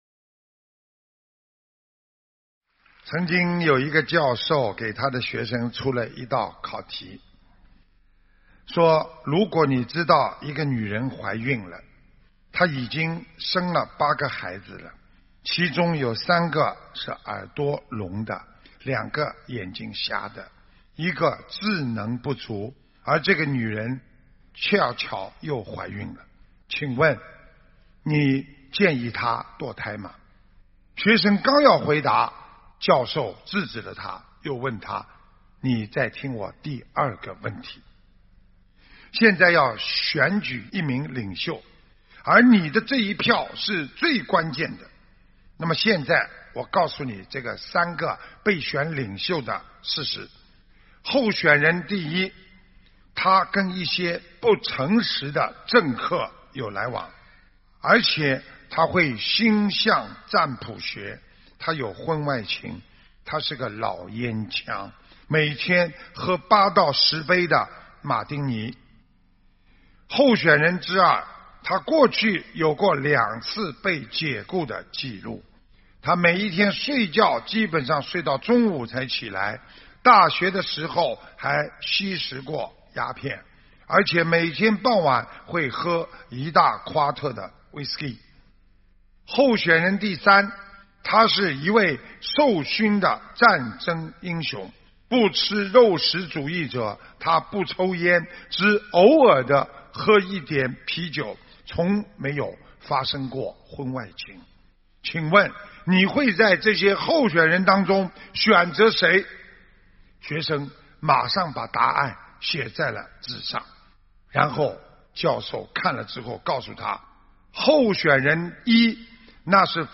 音频：【不要用既定价值去执着对与错】澳大利亚・悉尼开示2019年01月27日